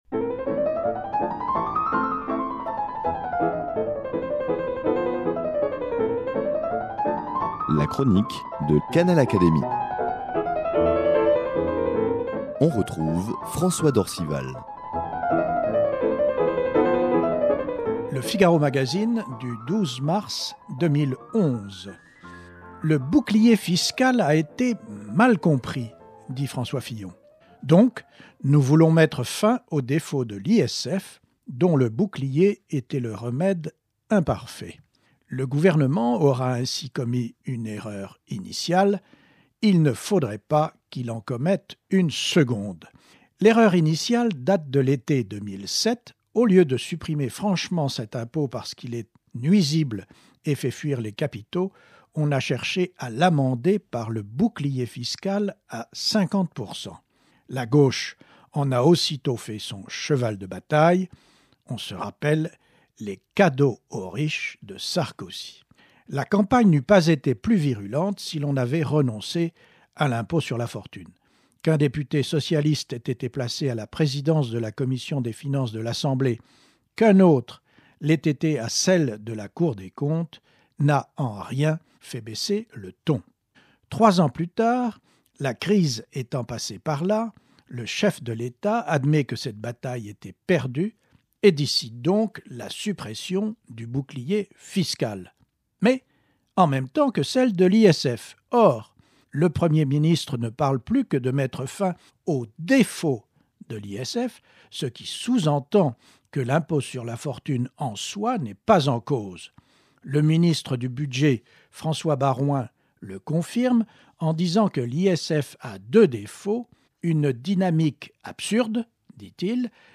La suppression du bouclier fiscal et celle de l’ISF suscitent beaucoup de remous en France et au sein du gouvernement. L’académicien journaliste François d’Orcival, éclaircit ici la fiscalité de notre pays en reprenant, au micro de Canal Académie, la chronique qu’il donne, le samedi, dans Le Figaro Magazine.
Elle est reprise ici par son auteur, avec l’aimable autorisation de l’hebdomadaire.